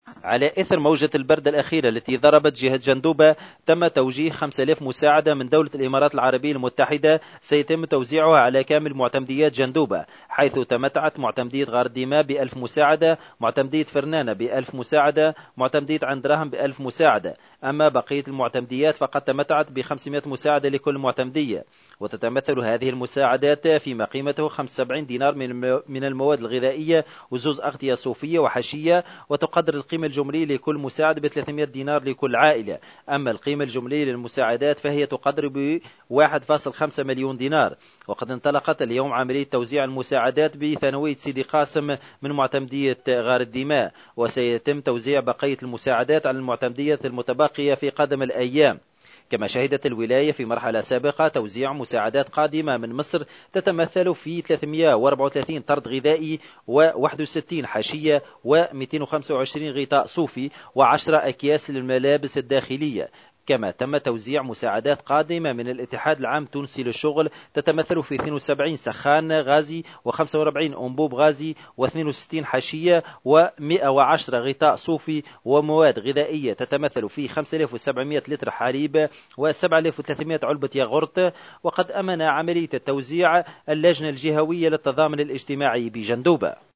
أكثر تفاصيل مع مراسلنا في جندوبة